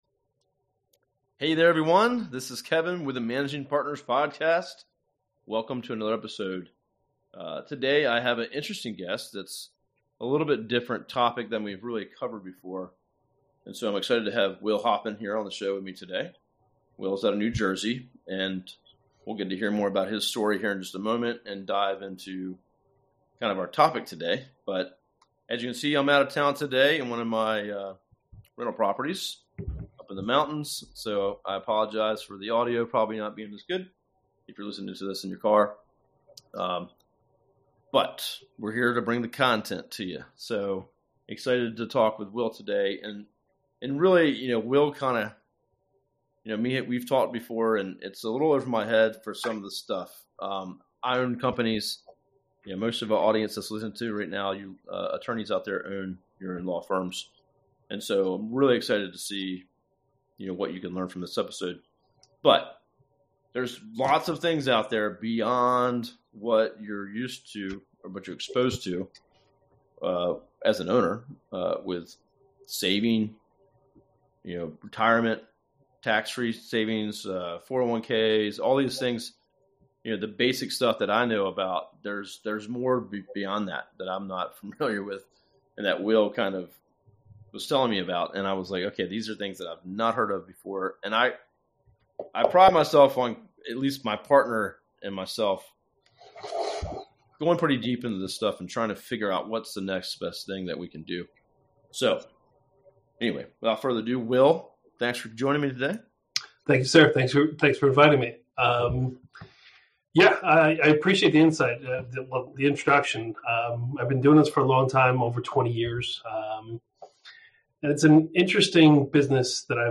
The Educational Interview meeting